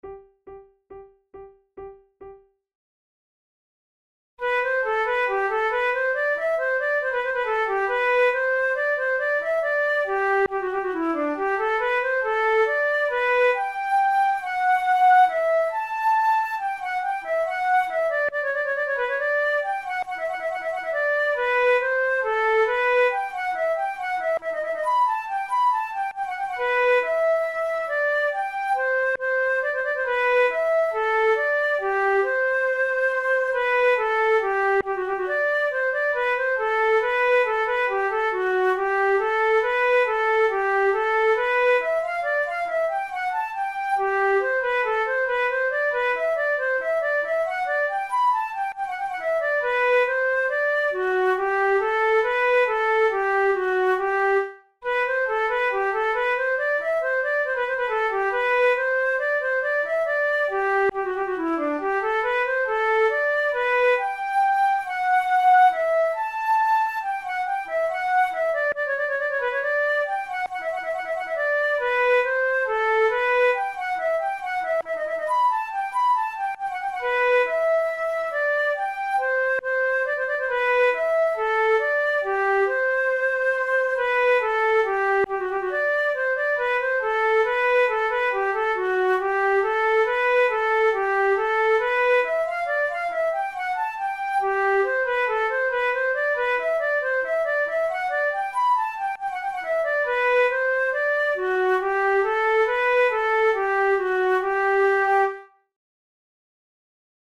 Flute duet by J.B. de Boismortier
This canon is the sixth duet in G major from the 55 Easy Pieces by Baroque composer Joseph Bodin de Boismortier.